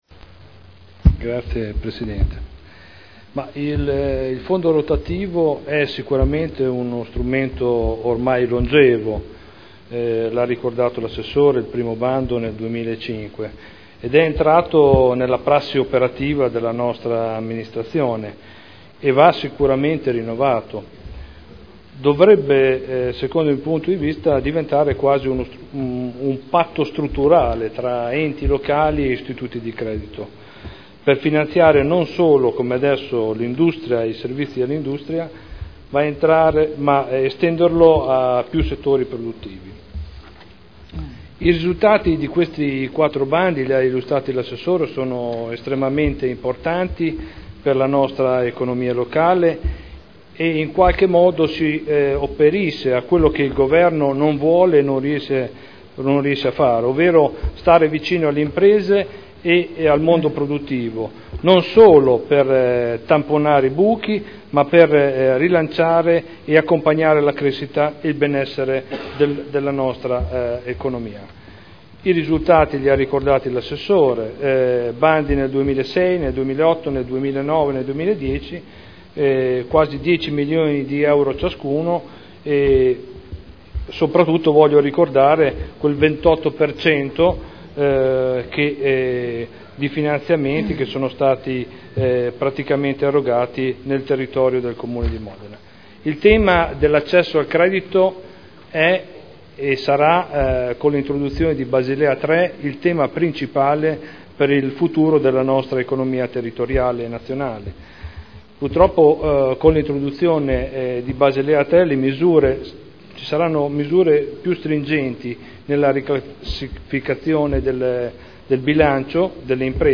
Seduta del 19/09/2011. Dibattito su proposta di deliberazione. Fondo provinciale per il sostegno all’innovazione delle imprese – Approvazione dello schema di convenzione per il rinnovo del fondo rotativo